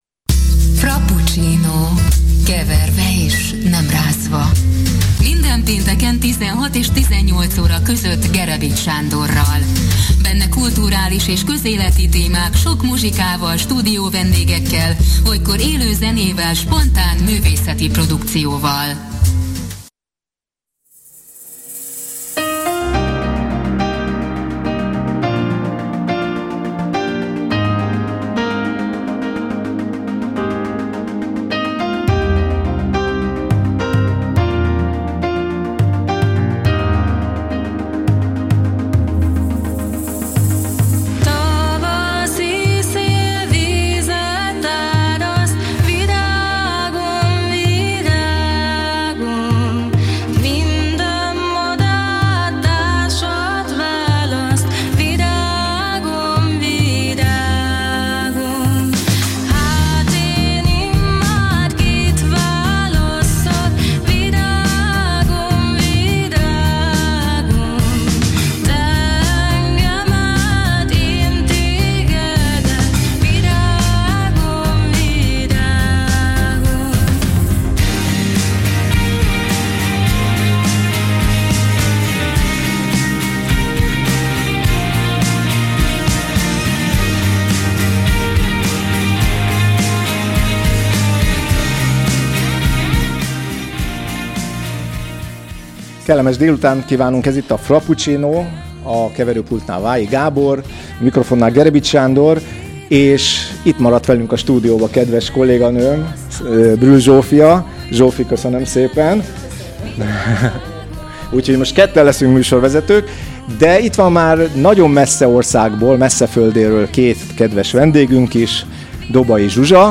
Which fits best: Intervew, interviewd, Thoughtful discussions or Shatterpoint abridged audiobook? Intervew